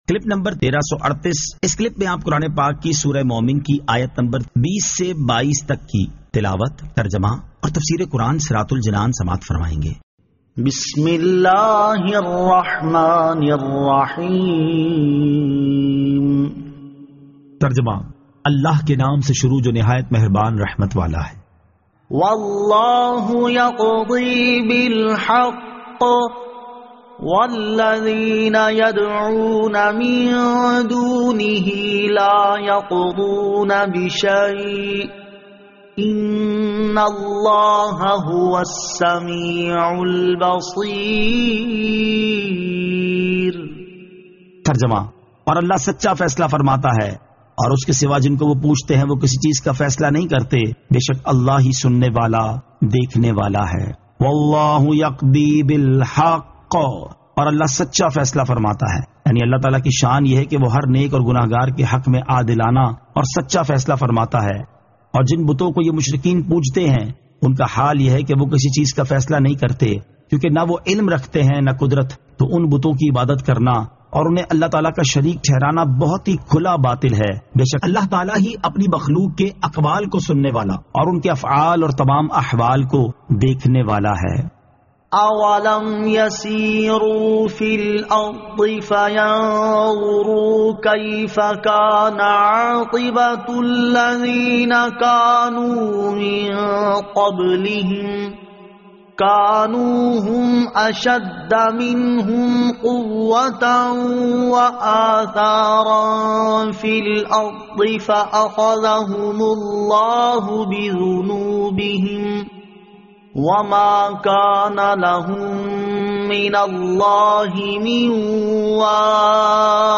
Surah Al-Mu'min 20 To 22 Tilawat , Tarjama , Tafseer